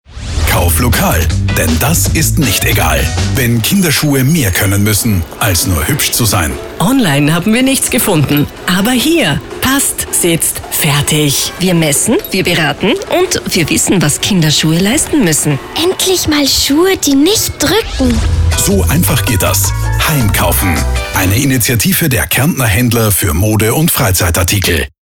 Radiospot Schuhe
radiospot-schuhe-2025.mp3